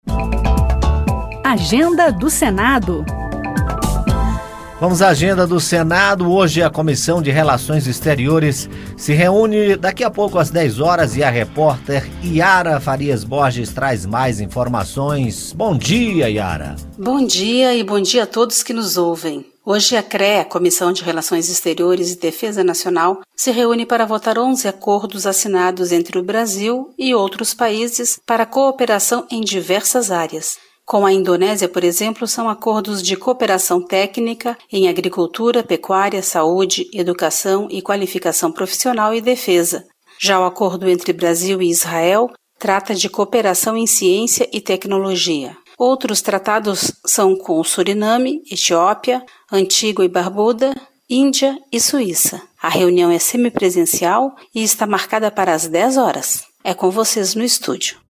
Comissão de Relações Exteriores (CRE) se reúne às 10 horas para aprovar textos de acordos de facilitação de investimentos, cooperação técnica e cooperação em ciência e tecnologia entre o governo brasileiro e diversos países. A repórter